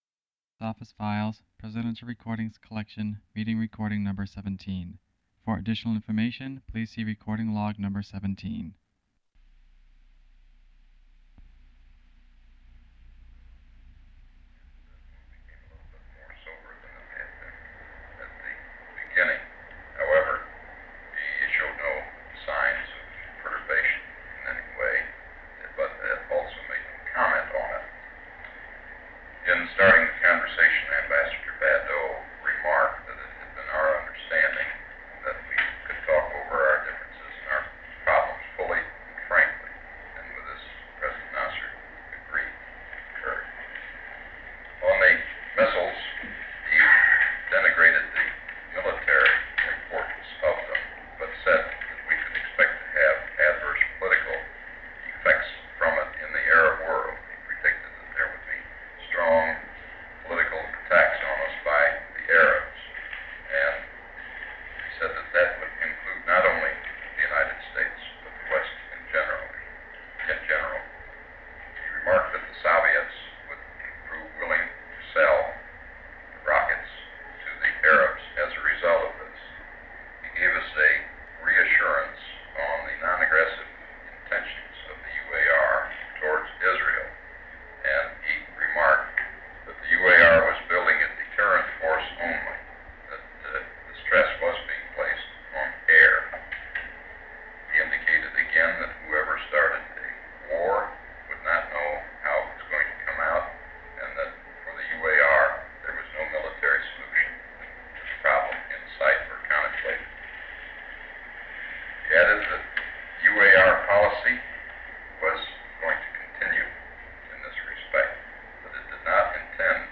Secret White House Tapes | John F. Kennedy Presidency Meeting on Arab-Israeli Questions Rewind 10 seconds Play/Pause Fast-forward 10 seconds 0:00 Download audio Previous Meetings: Tape 121/A57.